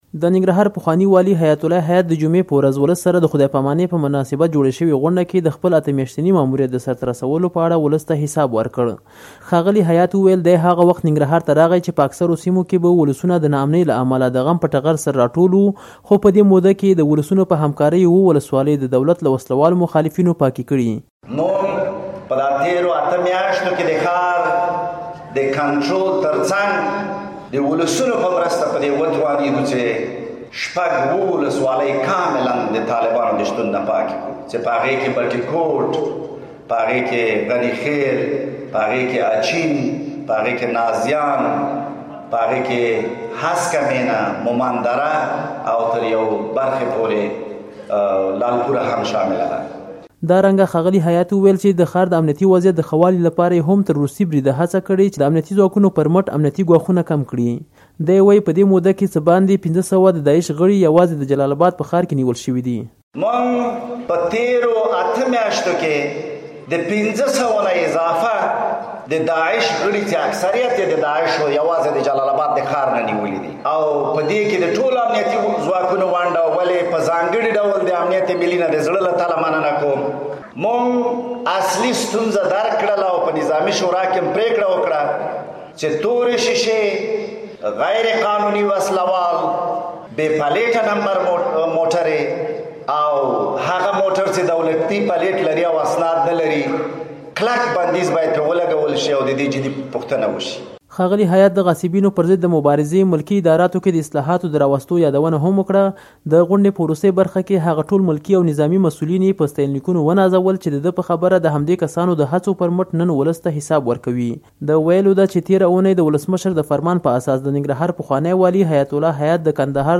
د ننګرهار راپور